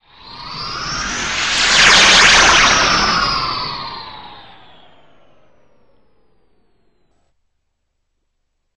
teleport2.ogg